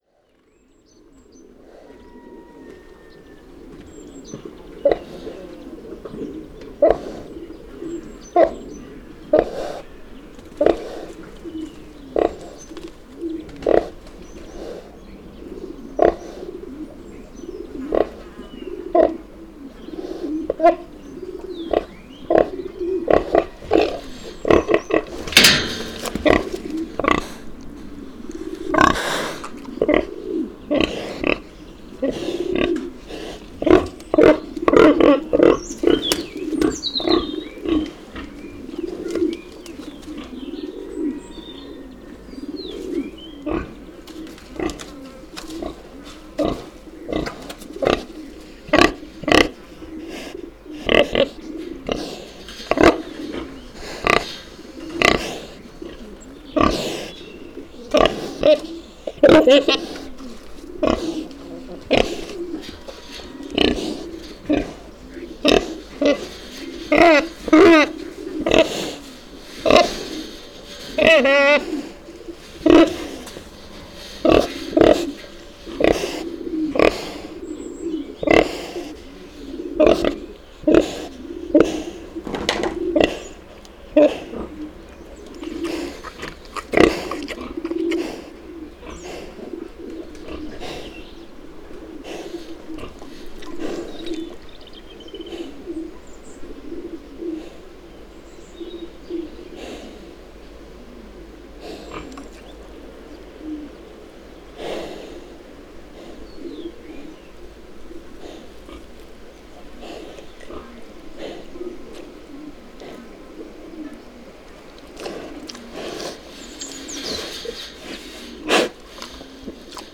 NODAR.00564 – Campo: Pocilga junto a galinheiro em Areeiro (de Campo)
Paisagem sonora de pocilga junto a galinheiro em Areeiro (de Campo), Campo a 8 Março 2016.